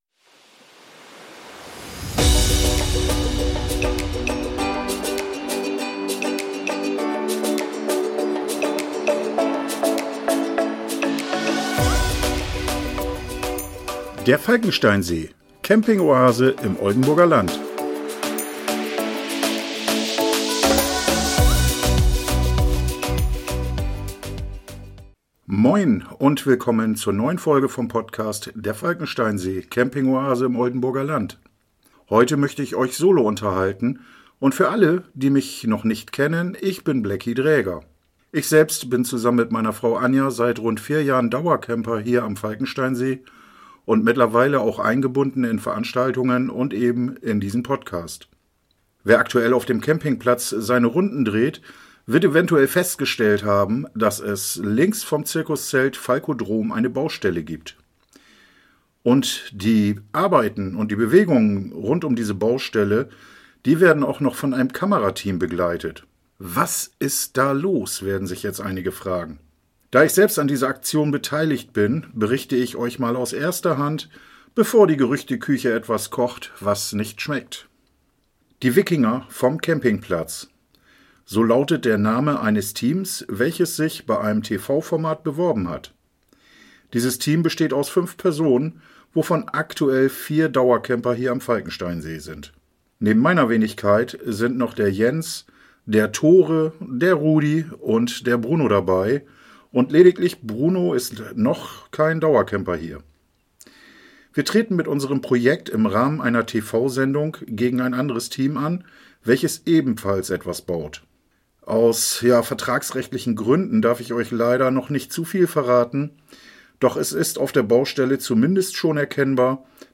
In dieser Folge bin ich heute mal solo, denn als direkt betroffener